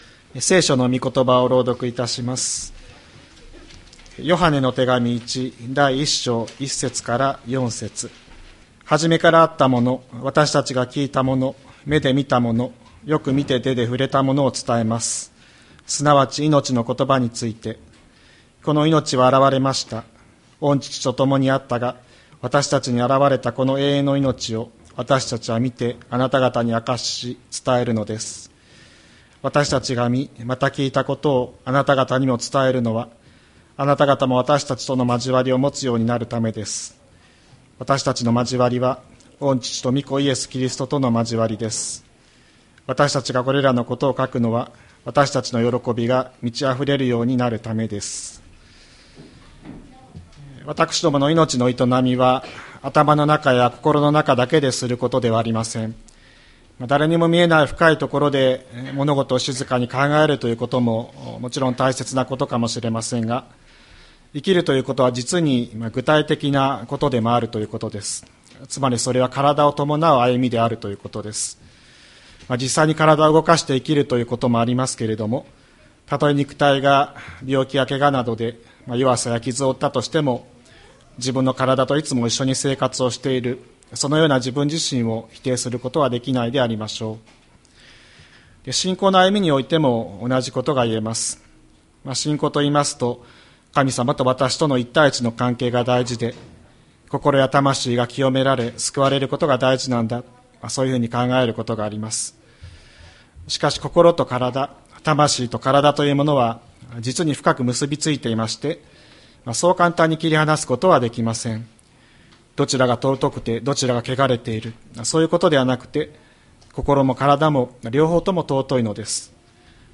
2025年03月30日朝の礼拝「いのちが造り出す交わり」吹田市千里山のキリスト教会
千里山教会 2025年03月30日の礼拝メッセージ。